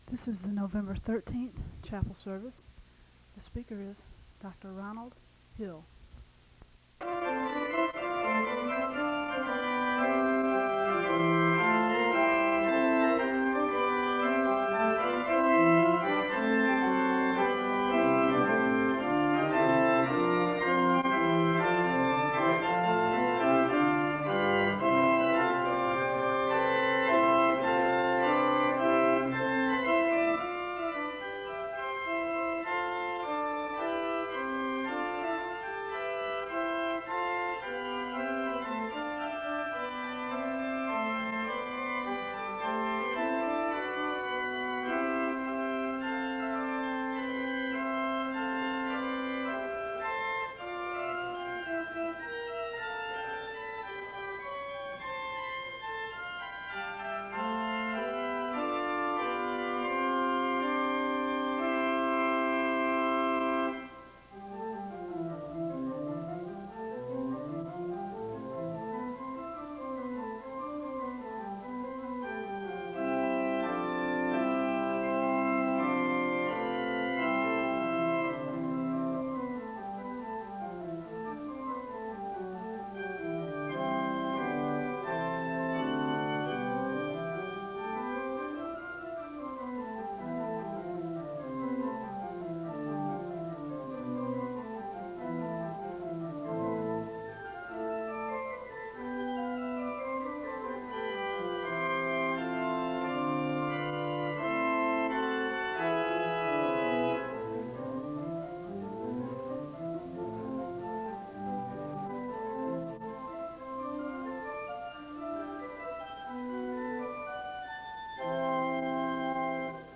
The service begins with organ music (00:00-05:55). The speaker reads a Psalm, and he gives a word of prayer (05:56-08:13).
The choir sings the anthem (11:23-15:14).
Location Wake Forest (N.C.)